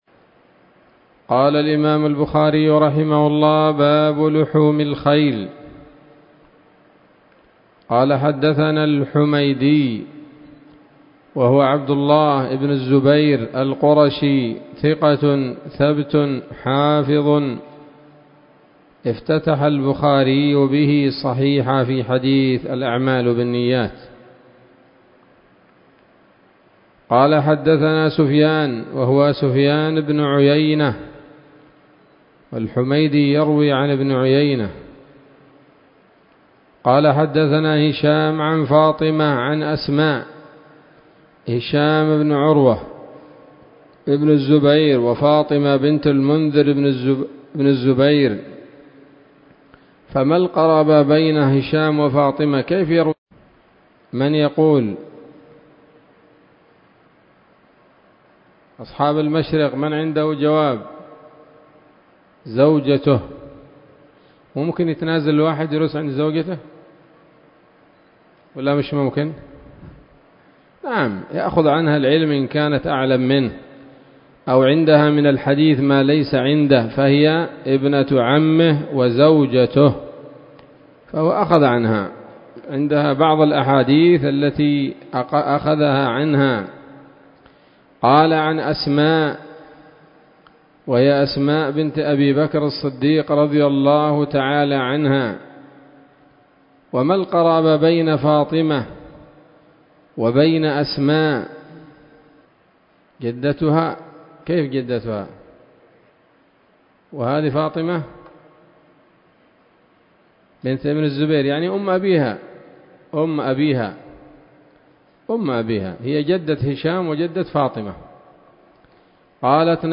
الدرس الثالث والعشرون من كتاب الذبائح والصيد من صحيح الإمام البخاري